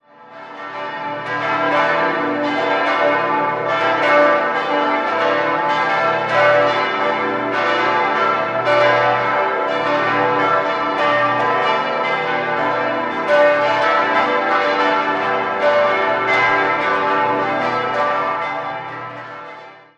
5-stimmiges TeDeum-Gloria-Geläute: h°-d'-e'-g'-a' Die zweitgrößte Glocke wurde im Jahr 1950 von der Gießerei Oberascher in München gegossen.
Das gesamte Geläut ist im Südturm untergebracht.